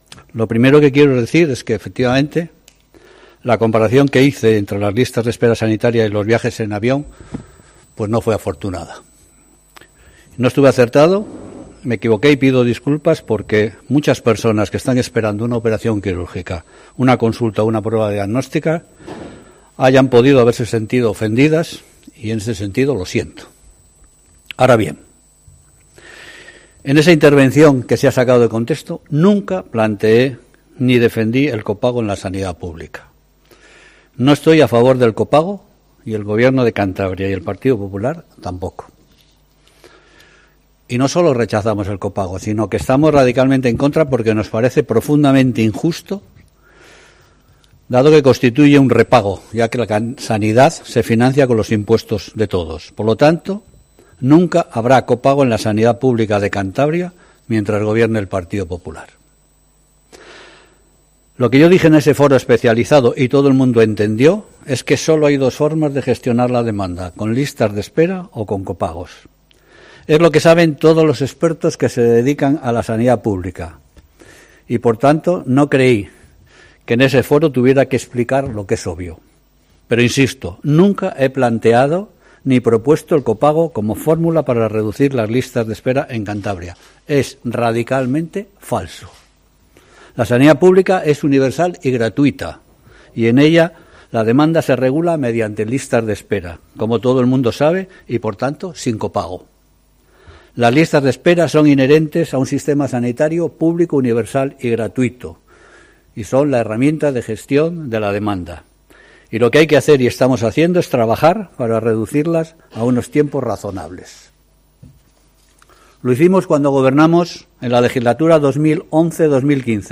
Pascual ha insistido en numerosas ocasiones durante su intervención pública que ni está a favor del copago ni lo ha defendido en ningún momento.